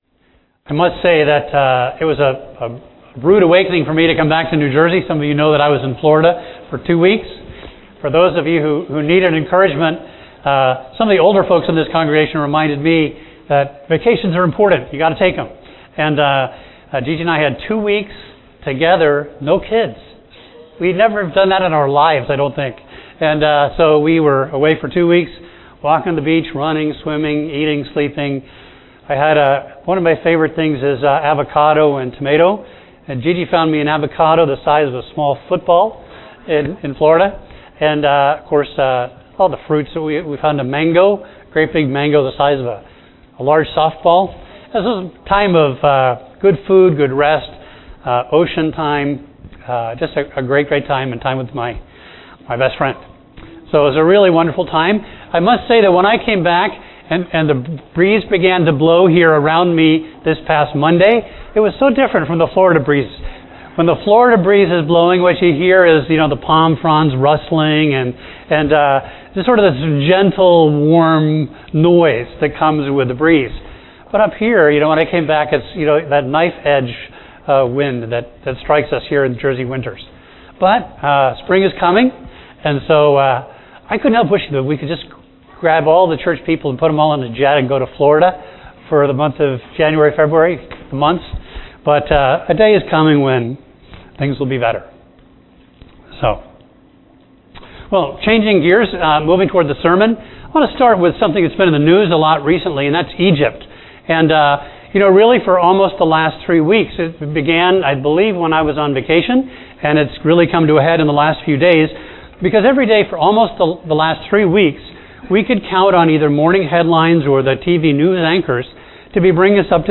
A message from the series "Elijah."